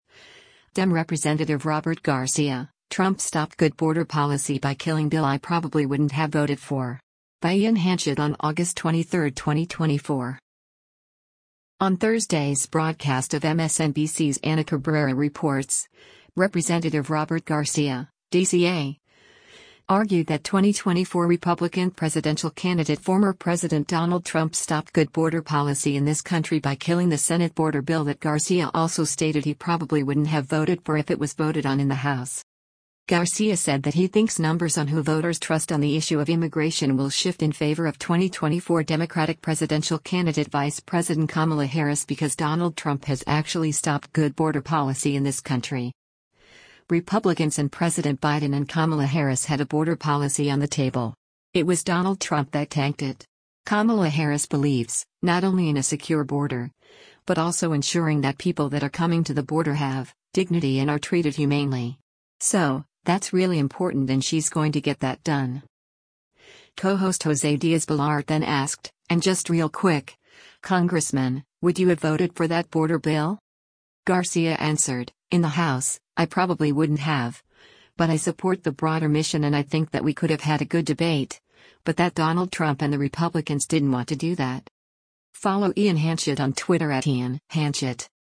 On Thursday’s broadcast of MSNBC’s “Ana Cabrera Reports,” Rep. Robert Garcia (D-CA) argued that 2024 Republican presidential candidate former President Donald Trump “stopped good border policy in this country” by killing the Senate border bill that Garcia also stated he “probably wouldn’t have” voted for if it was voted on in the House.
Co-host José Díaz-Balart then asked, “And just real quick, Congressman, would you have voted for that border bill?”